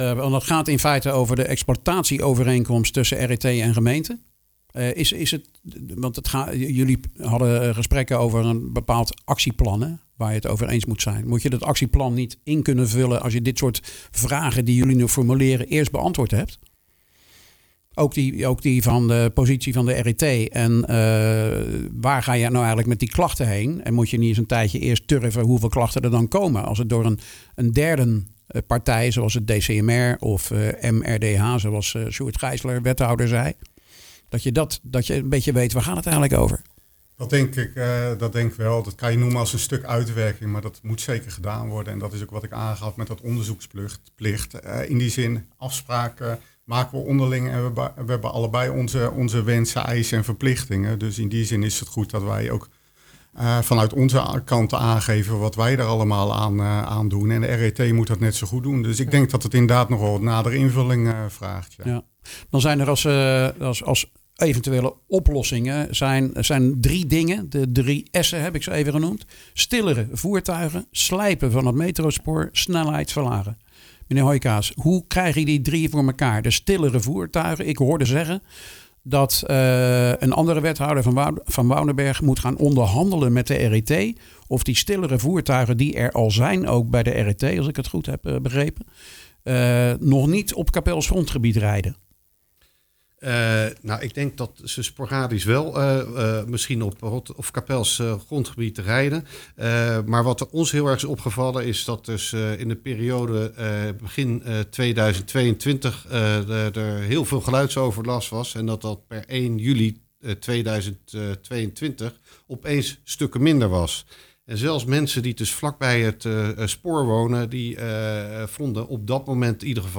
sprak met Alain Molengraaf (Leefbaar Capelle), Jasper Hooijkaas (D66) en Peter Heerens (ChristenUnie) over die data en de mogelijkheden van stillere